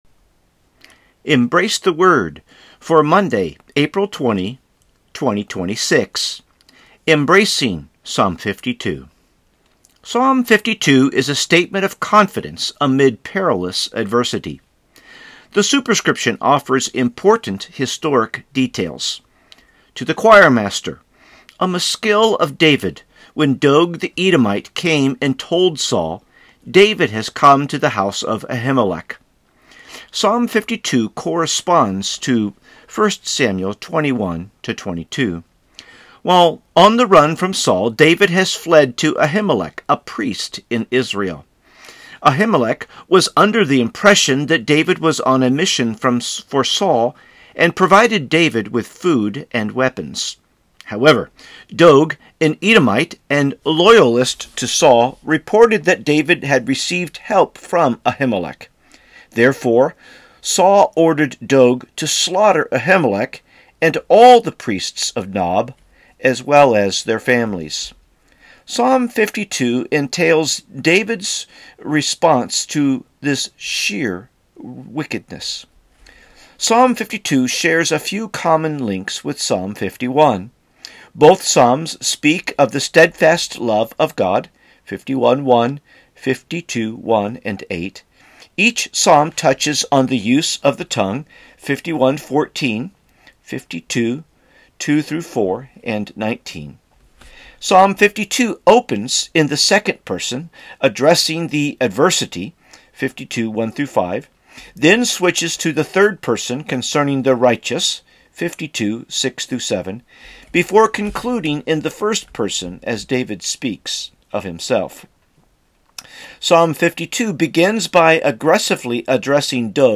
Sermons | First Baptist Church St Peters